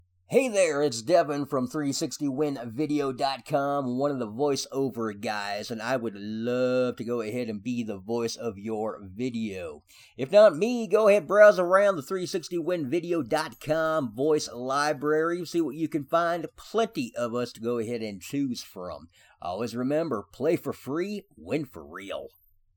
Voice Talent
Highly versatile, exceptional voice skills, and a charming, clear tone.